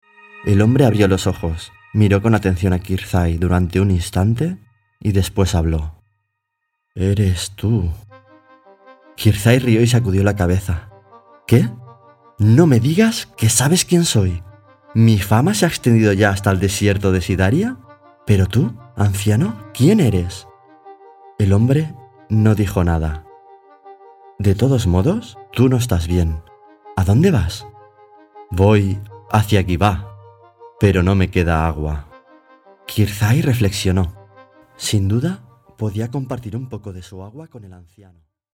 4 Audiolibros: